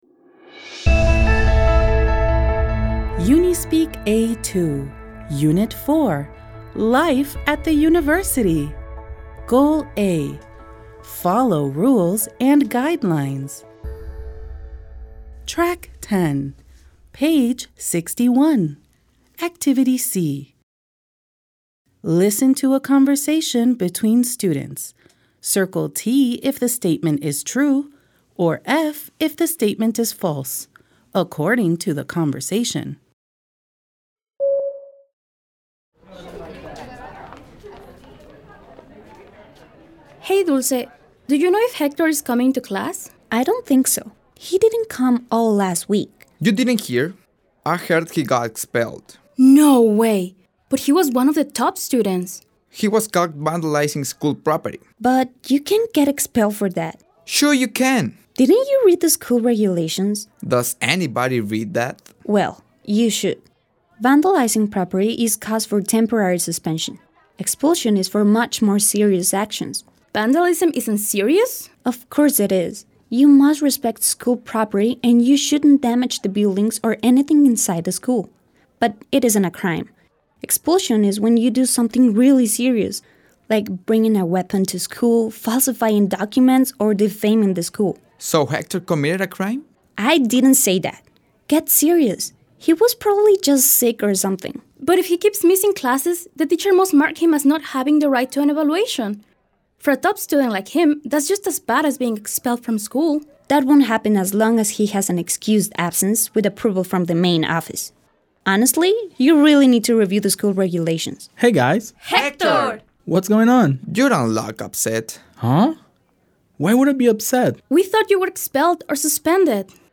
Listen to a conversation between students. Circle T if the statement is True or F if the statement is False according to the conversation.